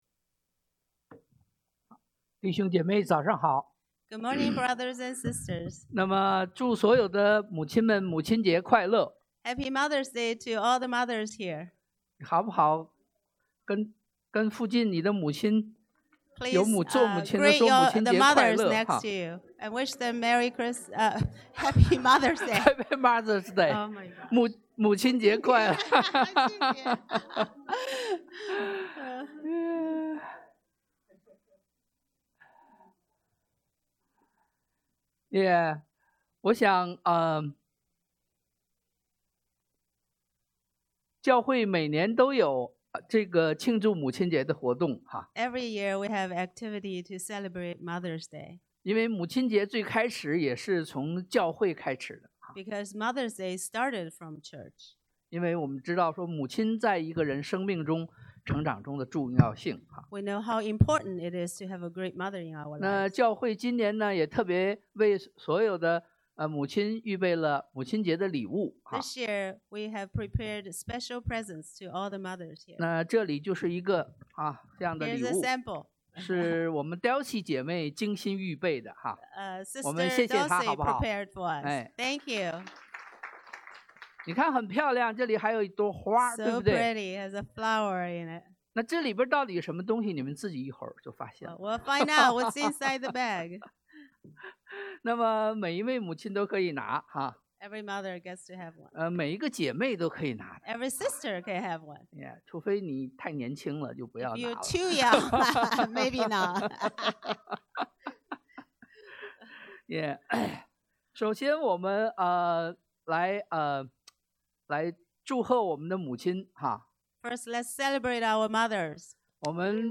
路 1:26-38;可 3:31-35;約 19:25-27 Service Type: Sunday AM « 2023-05-07 Pleasing God in Worship 討神喜悅的敬拜 2023-05-21 Holy!